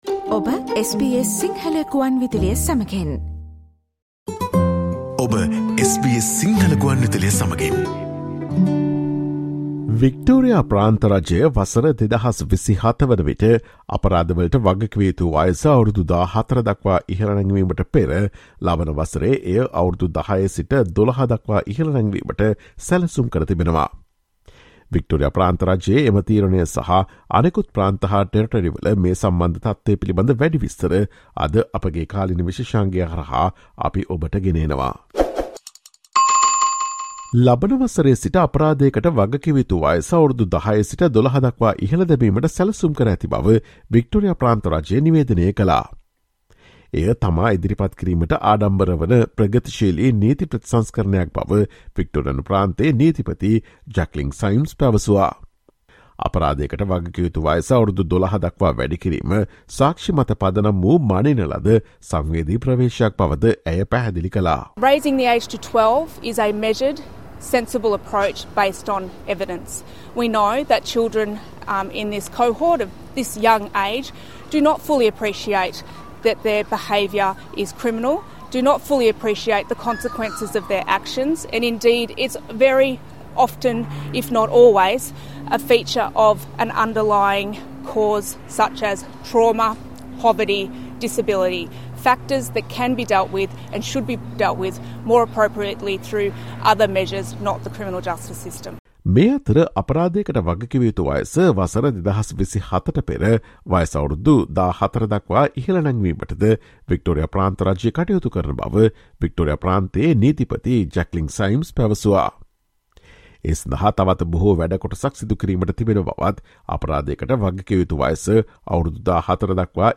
Listen to the SBS Sinhala radio current affair feature on the Victorian government's plans to raise the age of criminal responsibility from 10 to 12 years old next year, before lifting it to 14 by 2027.